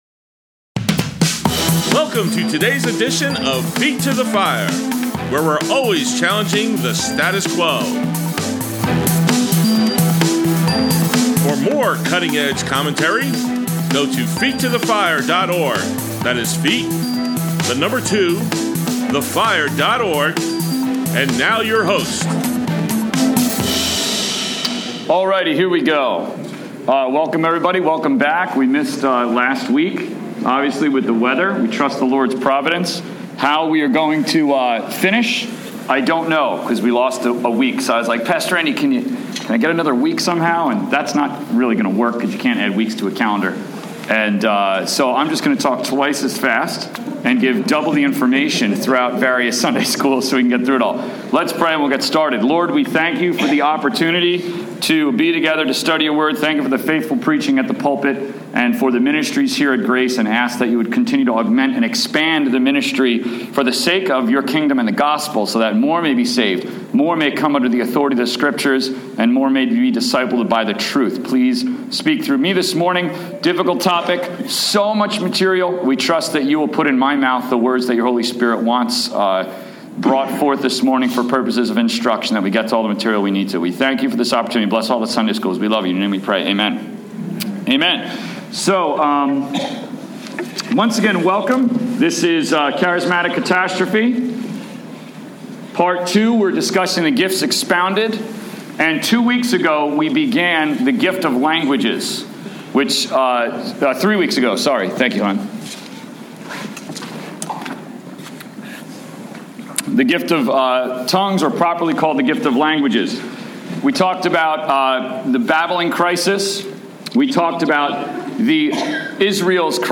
Grace Bible Church, Adult Sunday School, 2/22/18